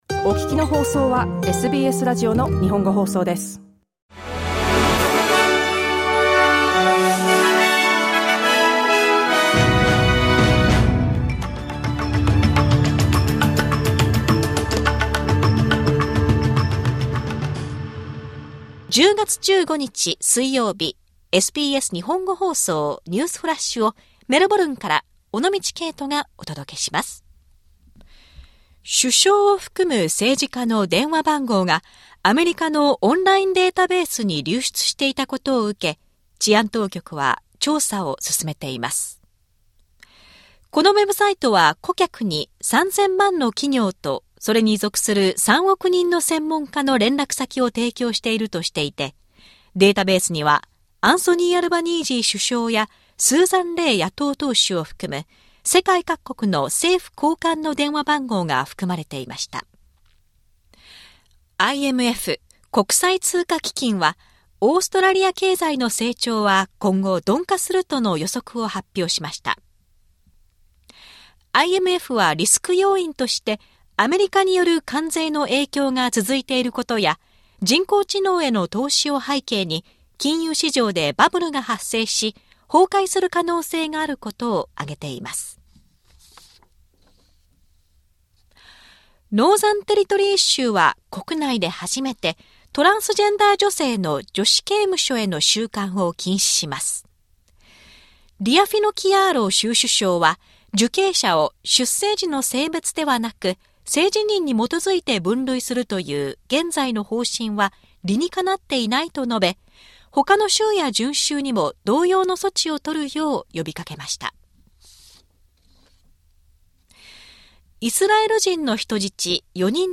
SBS日本語放送ニュースフラッシュ 10月15日 水曜日